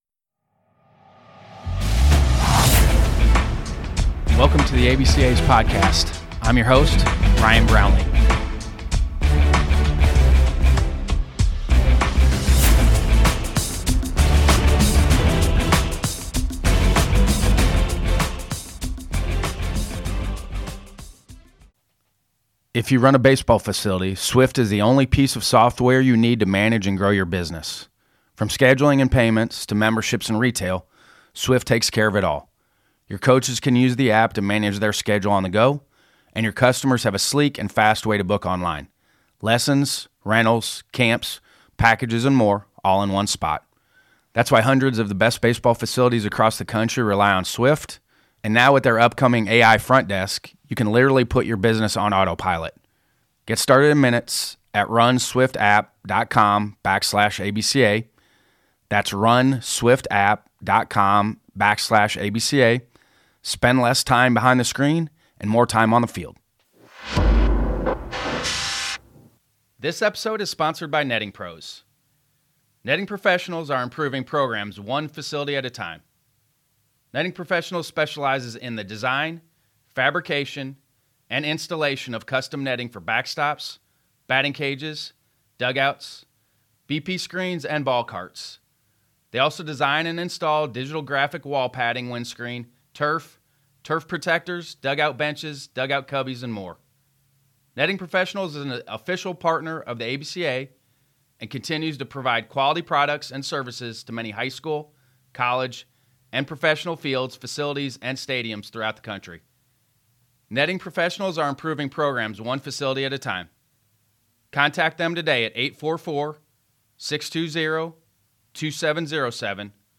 Coaches Wives Panel